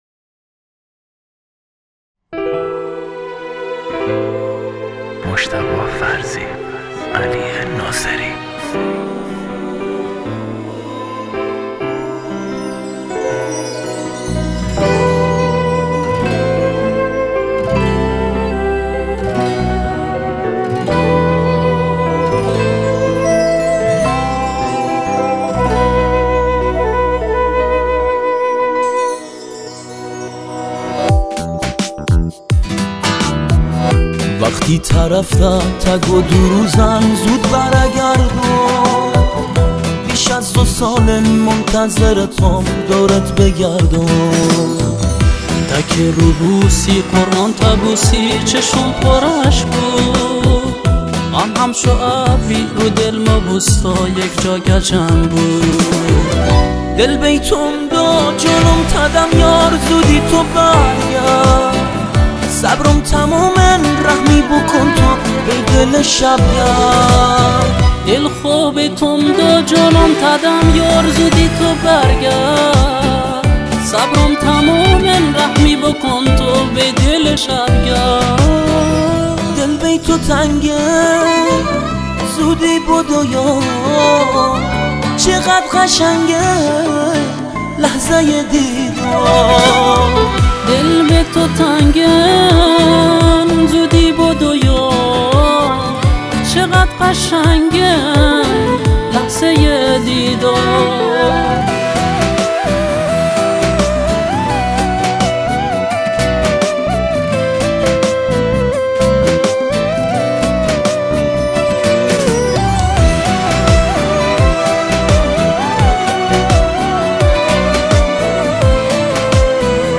آهنگ بندری